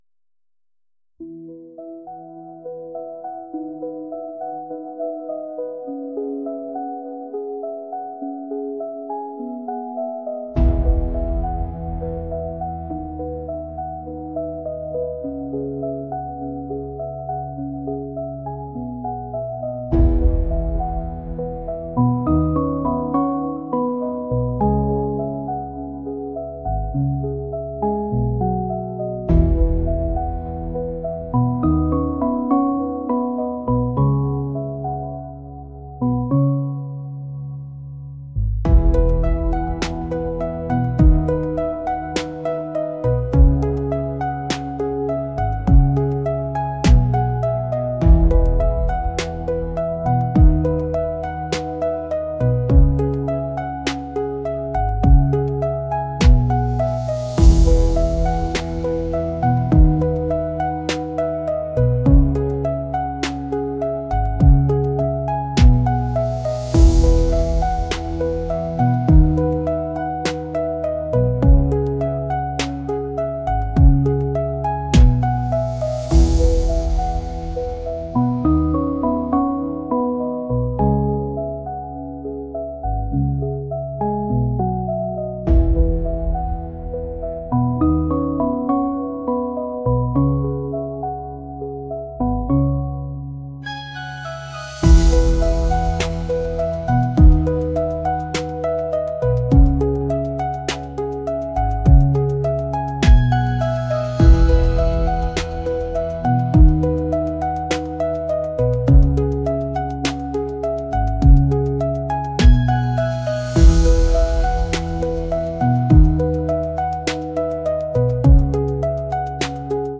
pop | ambient | indie